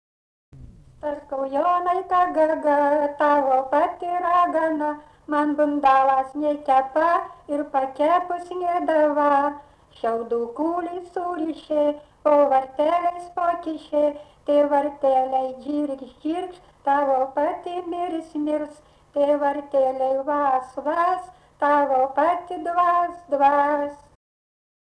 smulkieji žanrai
vokalinis